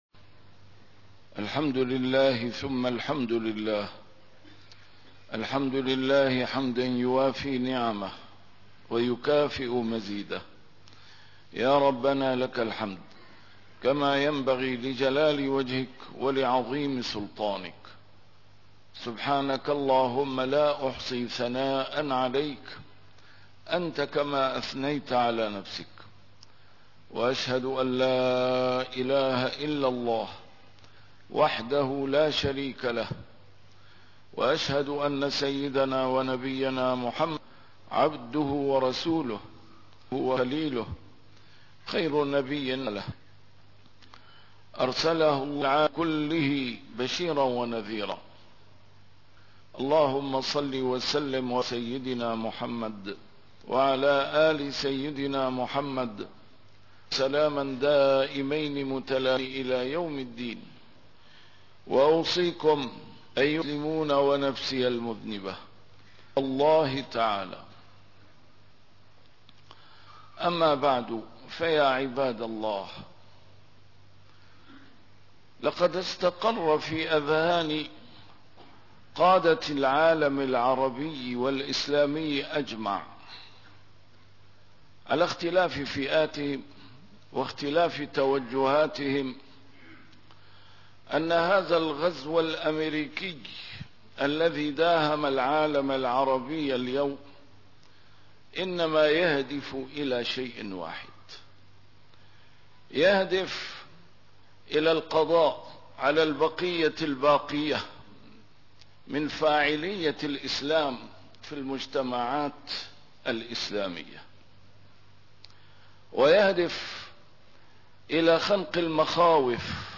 A MARTYR SCHOLAR: IMAM MUHAMMAD SAEED RAMADAN AL-BOUTI - الخطب - الإسلام ضرورة حصننا الإسلام